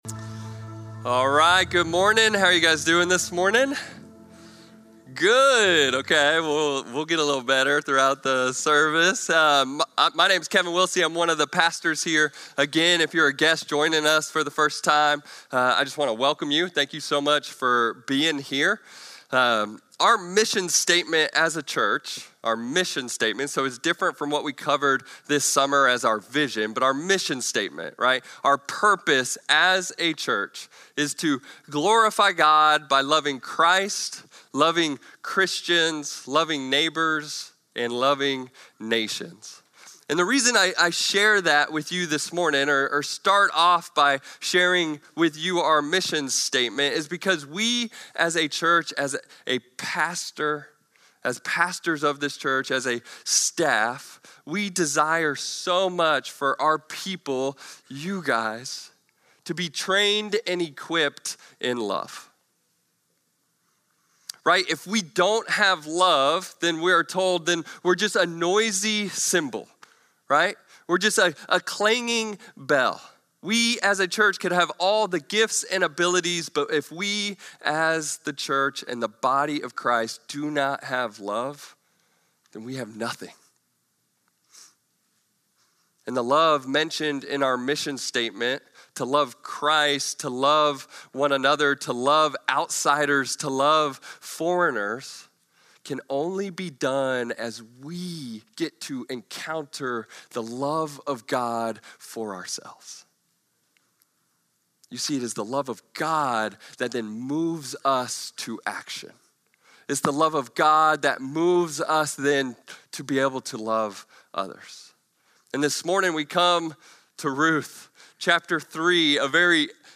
Norris Ferry Sermons Sept. 28, 2025 -- The Book of Ruth -- Ruth 3:1-18 Sep 28 2025 | 00:40:23 1x 00:00 / 00:40:23 Subscribe Share Spotify RSS Feed Share Link Embed